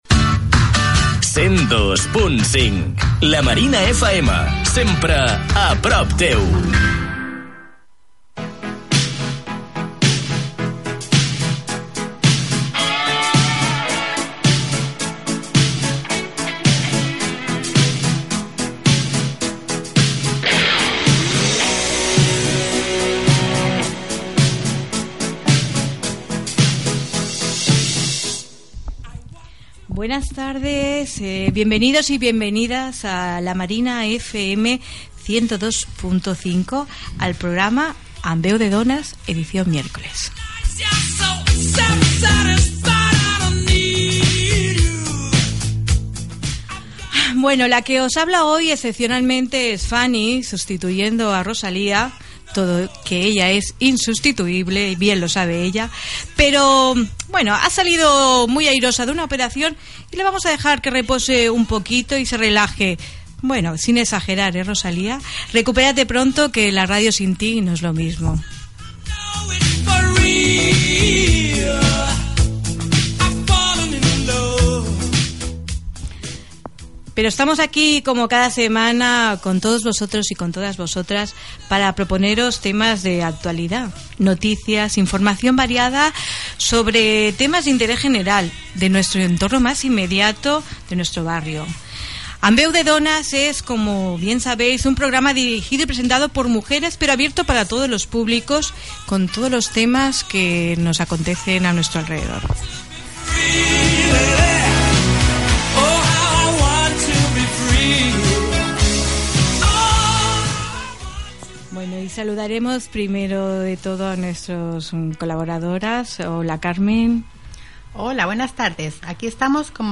Indicatiu de l'emissora, presentació del programa i de l'equip, entrevista